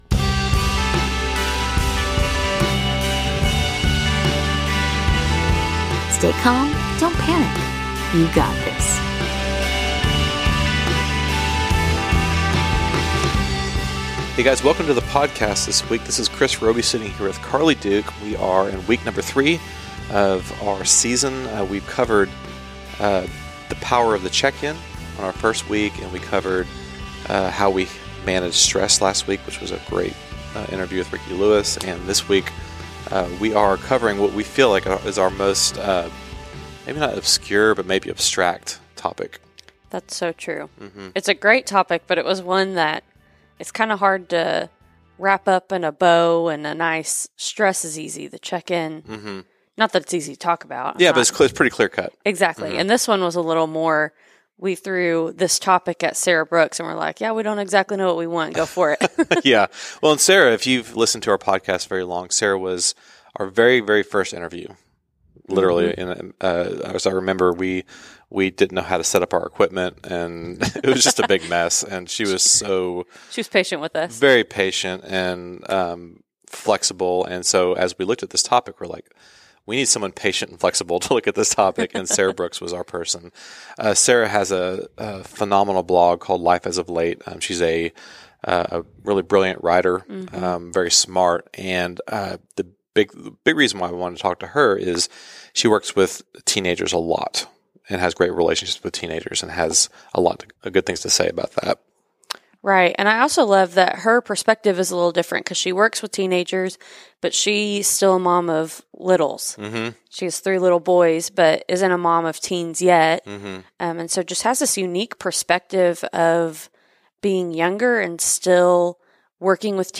Take a listen to this awesome conversation and walk away with some insight into the awesome lives of teenagers.